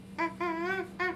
Baby Language - Ha-Ha (Monkey)
baby baby-language boy child grunt happy human infant sound effect free sound royalty free Animals